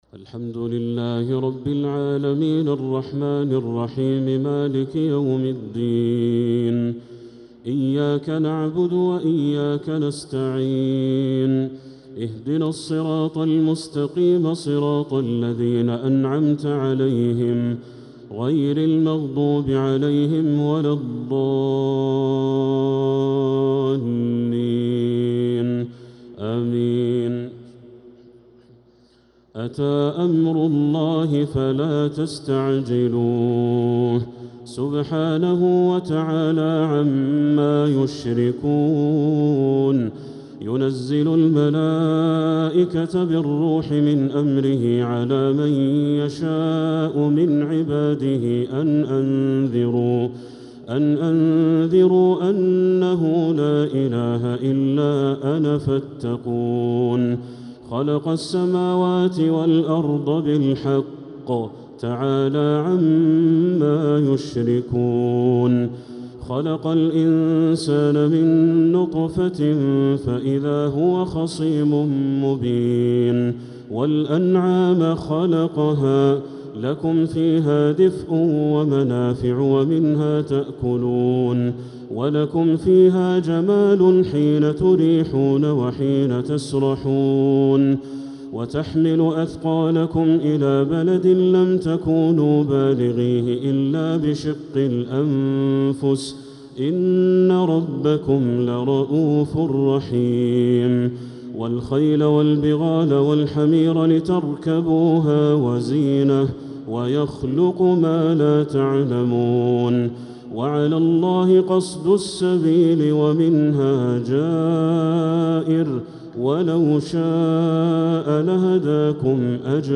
تراويح ليلة 18 رمضان 1446هـ فواتح سورة النحل (1-55) | Taraweeh 18th night Ramadan 1446H Surat An-Nahl > تراويح الحرم المكي عام 1446 🕋 > التراويح - تلاوات الحرمين